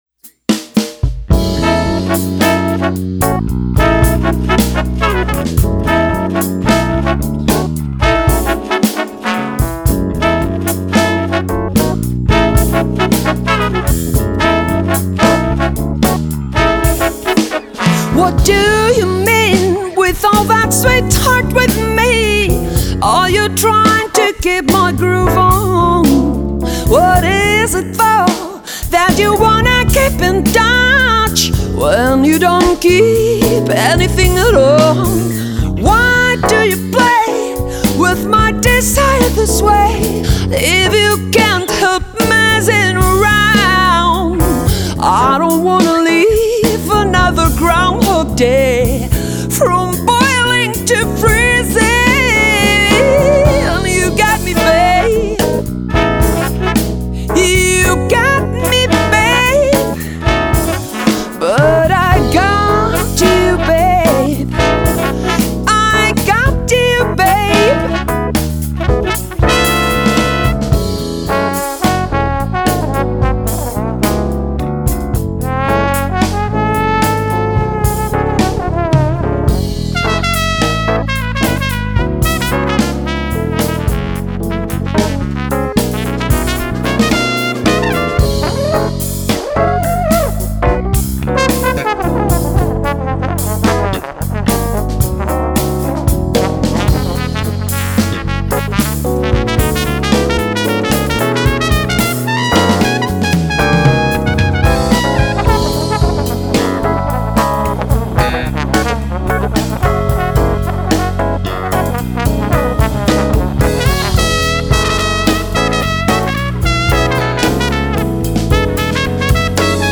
Жанр: Folk.